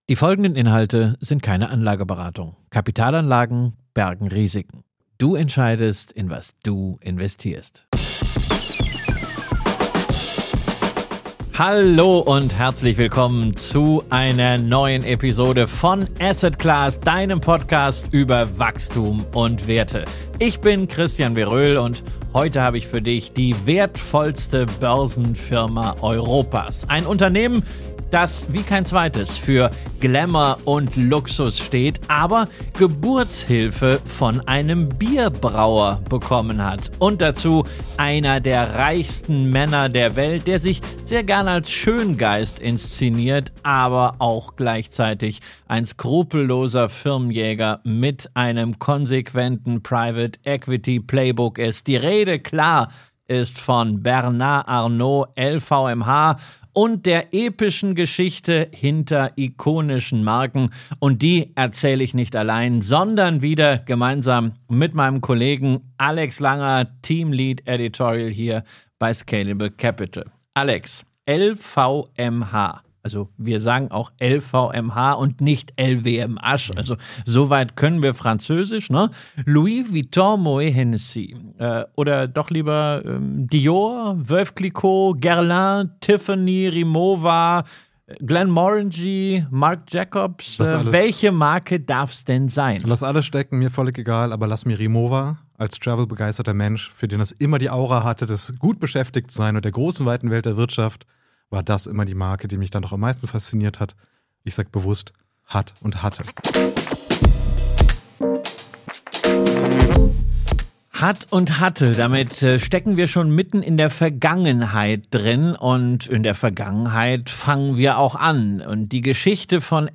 Solche und ähnliche Fragen diskutieren wir in Interviews mit externen Gästen und Experten von Scalable Capital – fundiert und leicht verständlich.